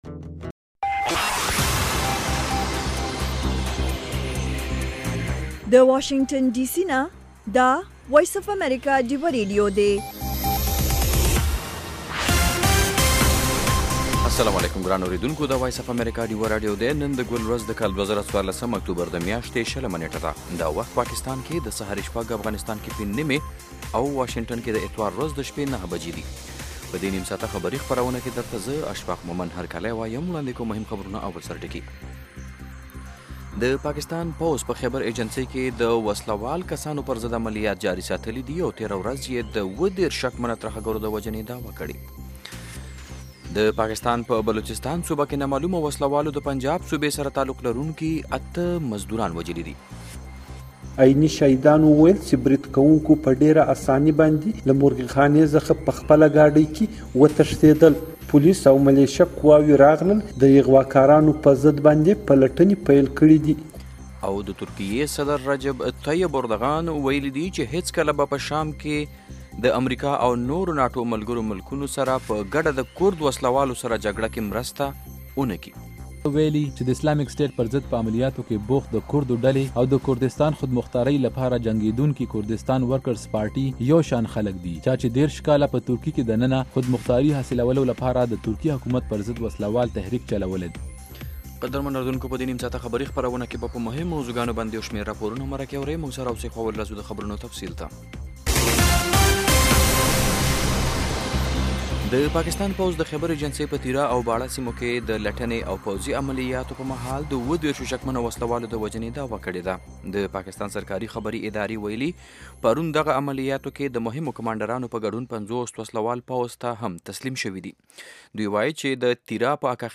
خبرونه - 0100